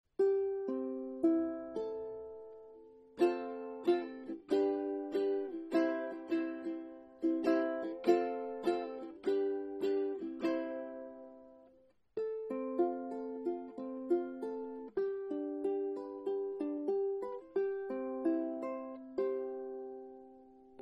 Meine Soundbeispiele sind alle am gleichen Tag mit einem Zoom H2 aufgenommen.
Gespielt habe ich nur ein paar Akkorde.
Kopus massiv Mango, Worth-Saiten